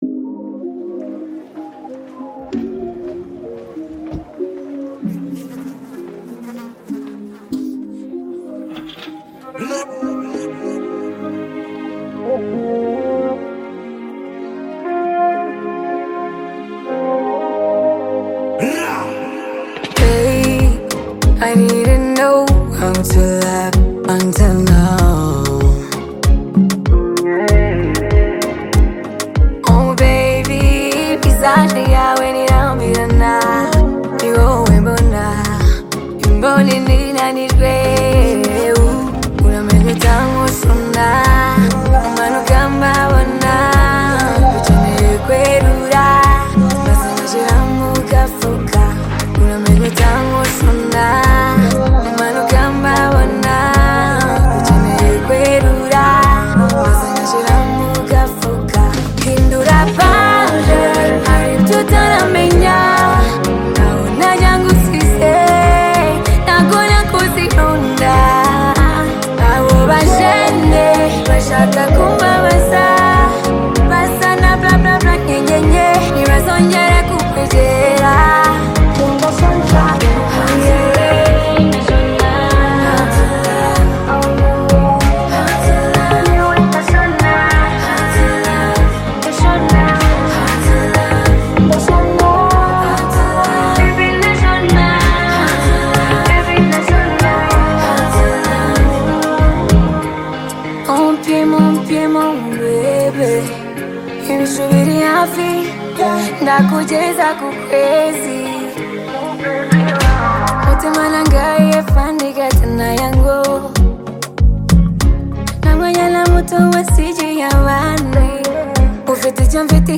gives the song a polished yet organic feel
The song’s vibrant instrumentation